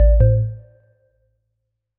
Melodic Power On 3.wav